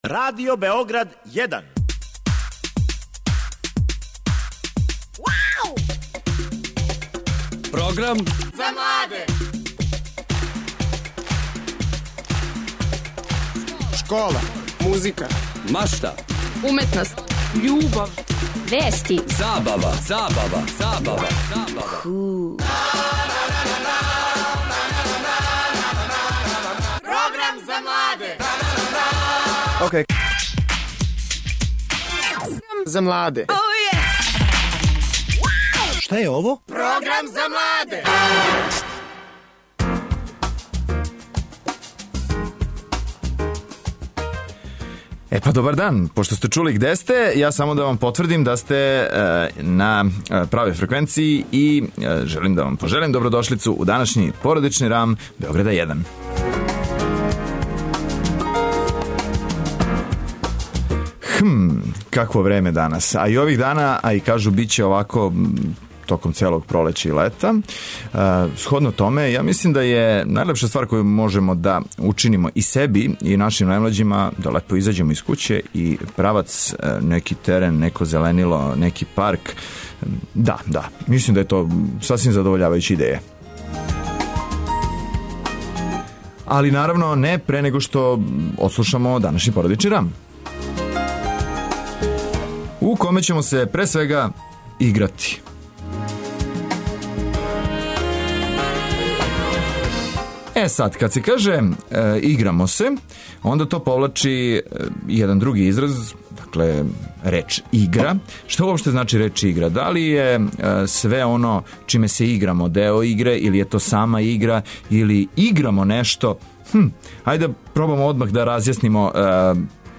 Гости: Деца играчи рагби клуба "Сингидунум".